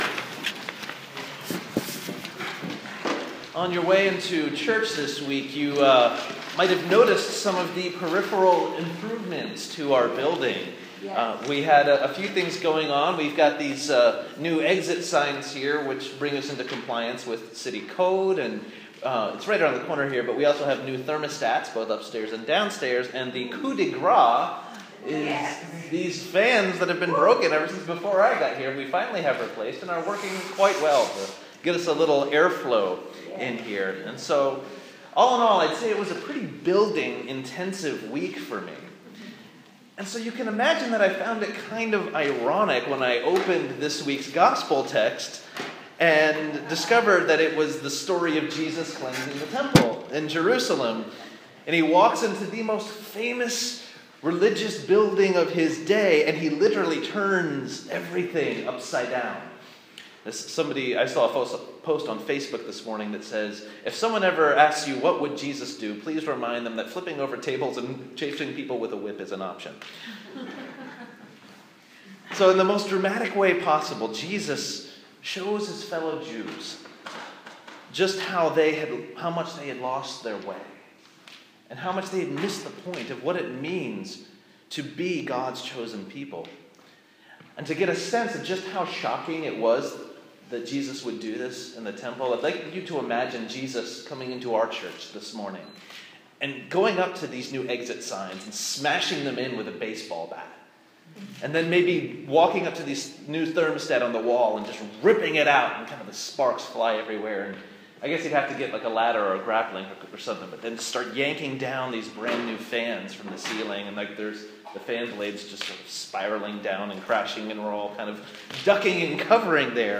Sermon for the Third Sunday in Lent
sermon-lent-3-2015.m4a